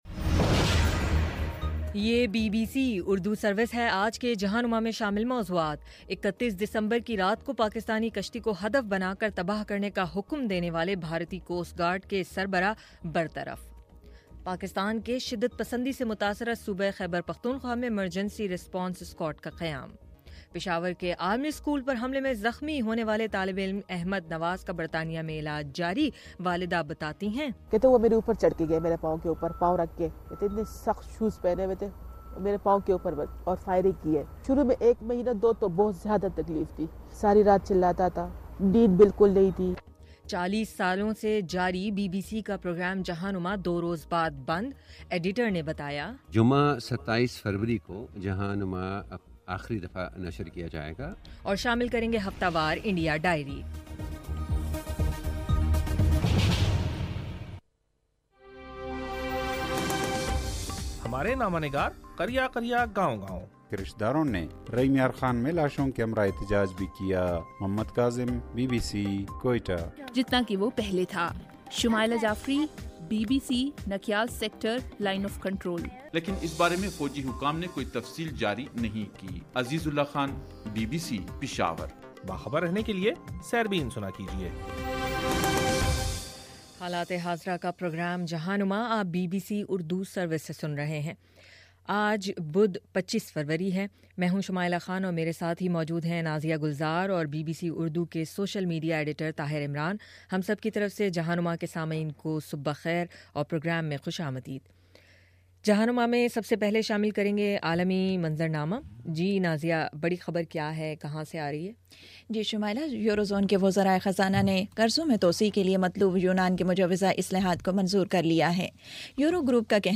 بدھ 25 فروری کا جہاں نما ریڈیو پروگرام